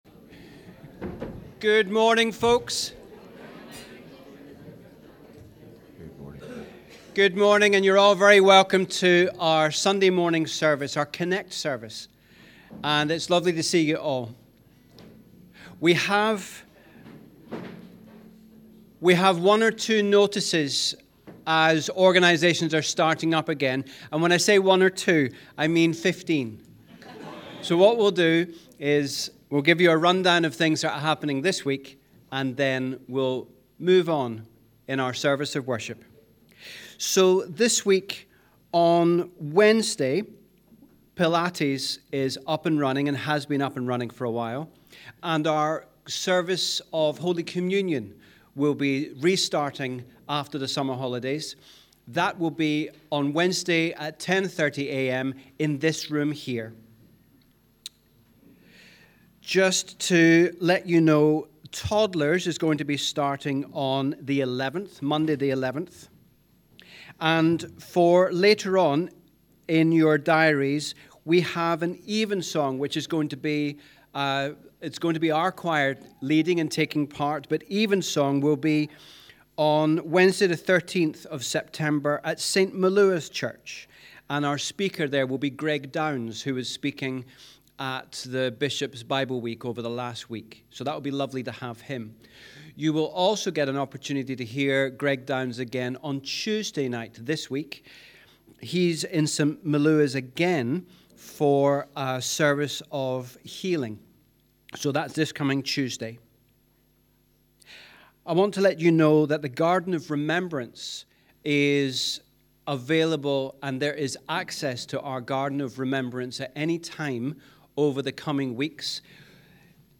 We warmly welcome you to our CONNEC+ service as we worship together on the 13th Sunday after Trinity.